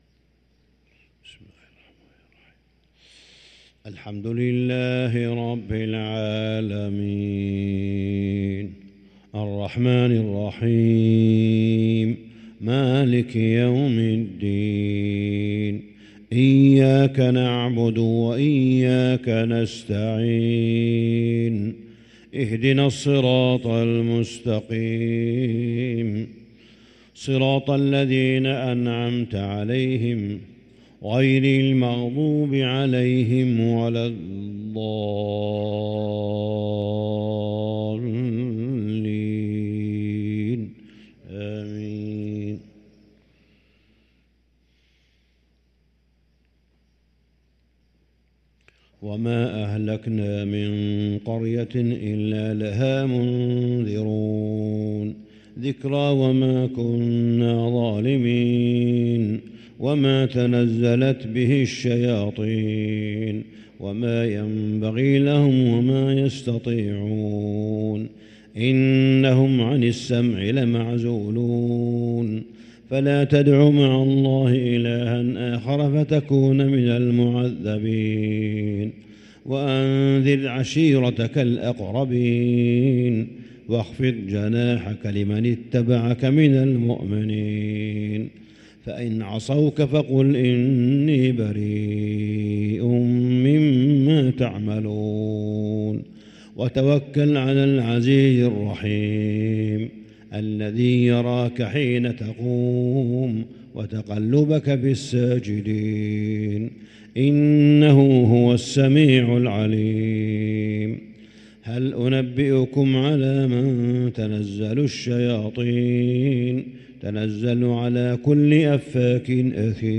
صلاة الفجر للقارئ صالح بن حميد 7 رمضان 1444 هـ
تِلَاوَات الْحَرَمَيْن .